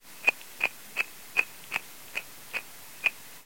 浴缸 " 擦擦2
描述：擦我的水听器的电缆
标签： 粗糙 水下 水听器
声道立体声